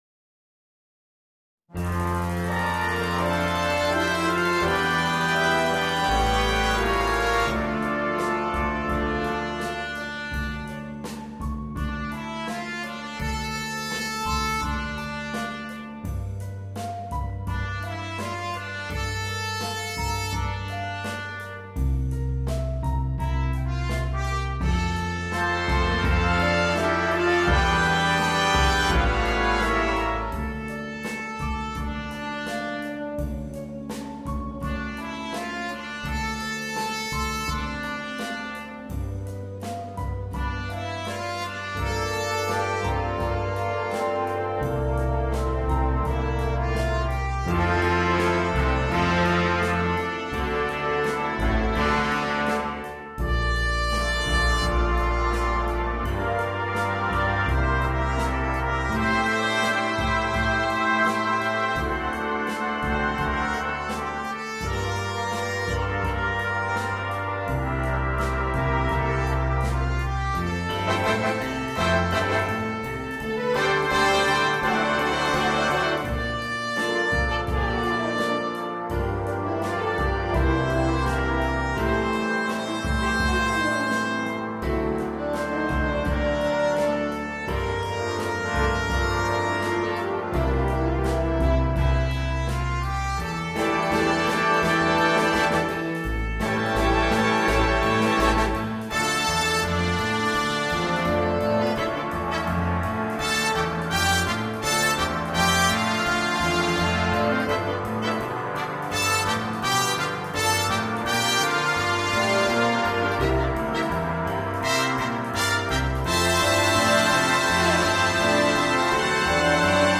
на биг-бэнд